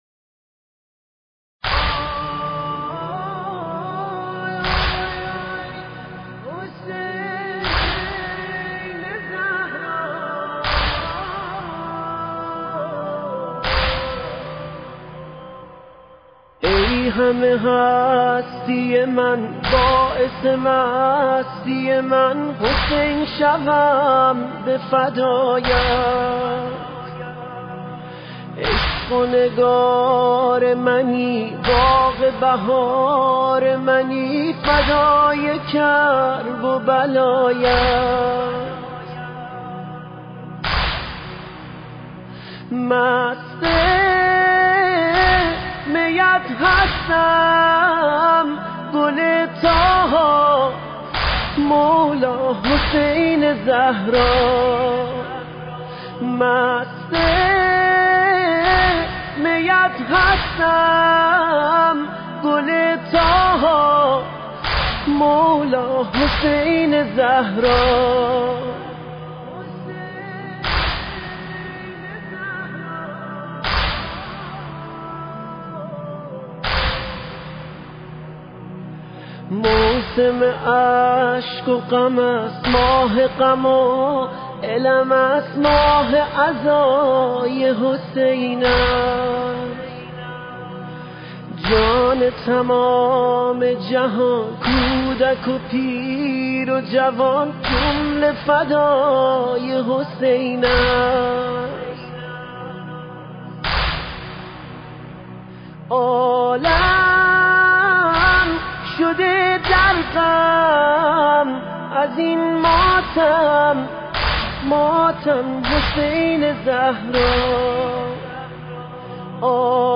مداحی حسین شوم به فدایت